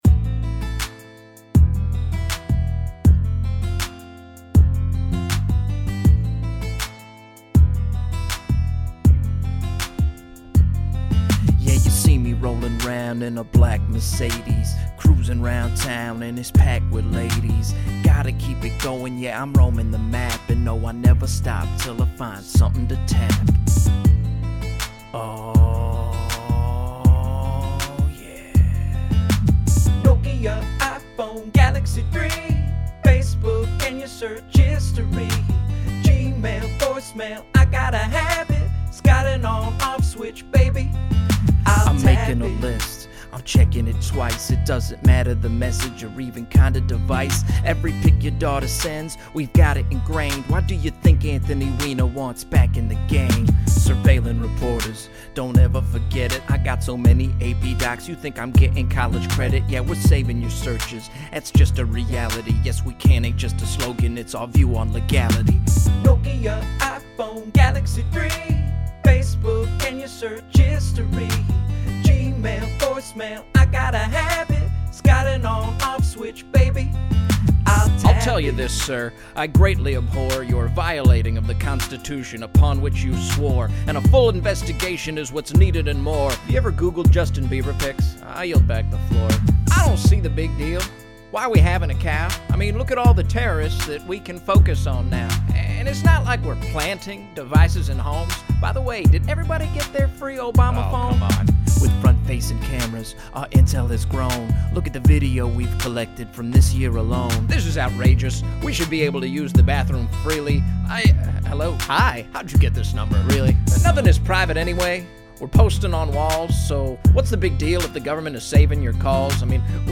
Government surveillance never sounded so smooth.